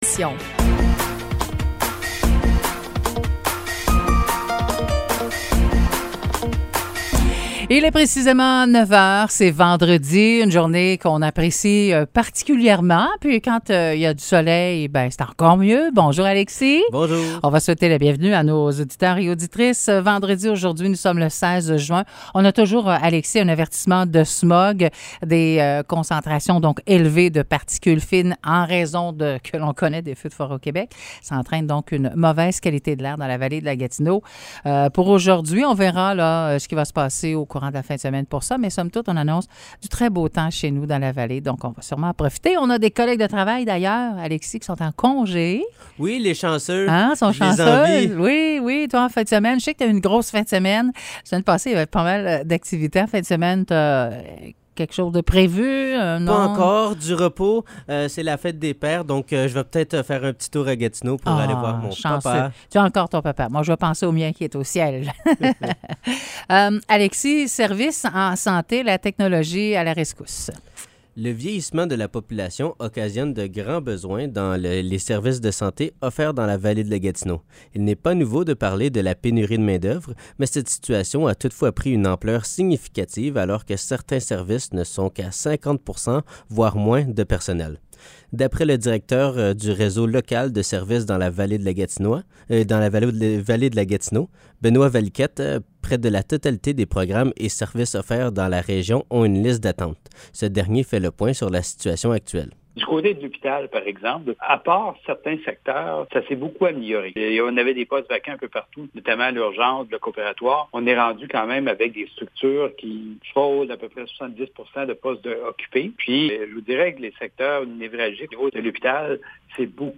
Nouvelles locales - 16 juin 2023 - 9 h